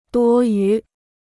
多余 (duō yú) Free Chinese Dictionary